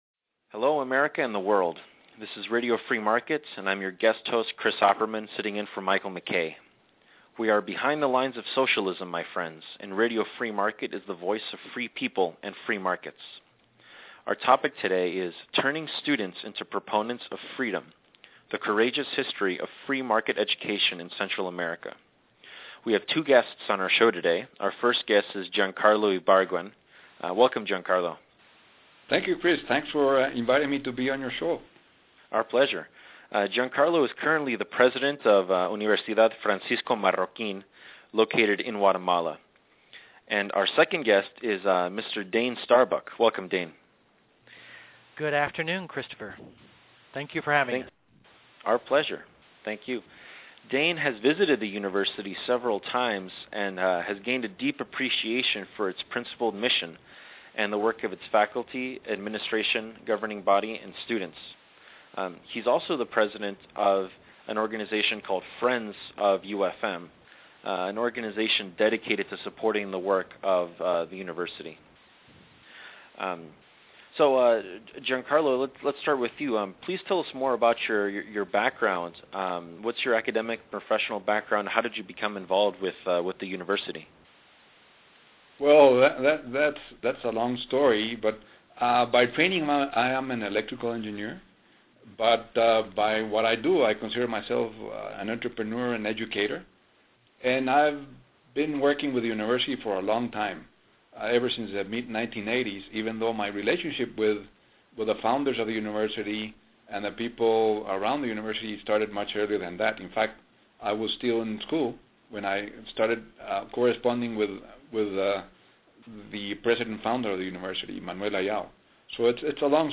Please join us for a fascinating interview